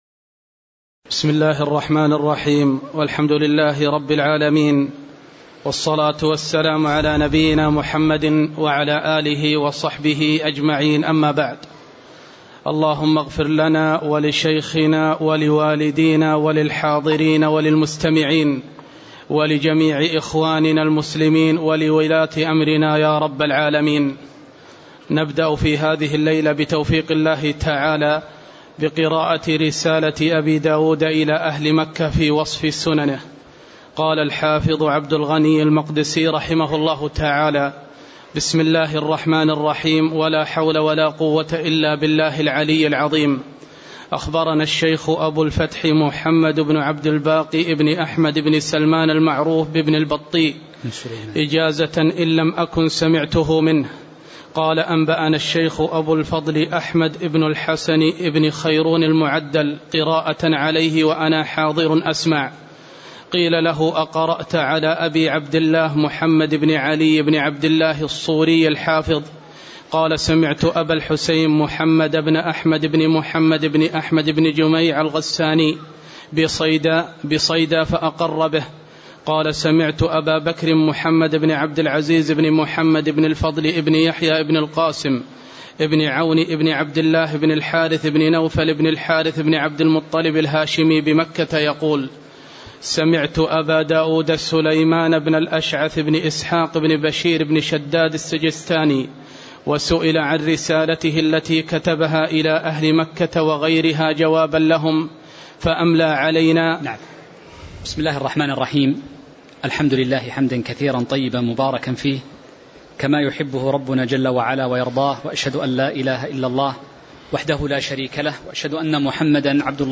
تاريخ النشر ١٢ شعبان ١٤٤٤ هـ المكان: المسجد النبوي الشيخ